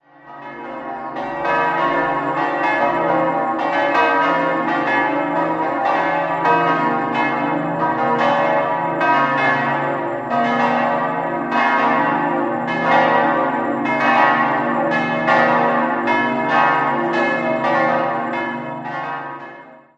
Paulinus und Trierische Märtyrer h°-11 2.365 kg 1.570 mm 1821 Perrin Nikolaus und Donatus cis'-9 1.773,5 kg 1.420 mm 1821 Perrin Michael und Walburga d'-5 1.238,5 kg 1.273 mm 1821 Perrin Petrus und Johannes Nepomuk e'-4 1.016,5 kg 1.168 mm 1821 Perrin Quelle